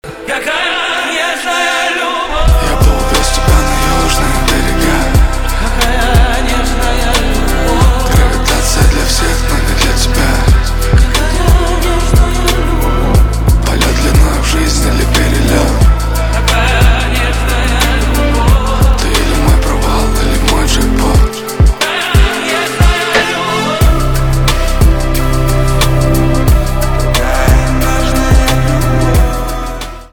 Романтические рингтоны
поп
чувственные , битовые , басы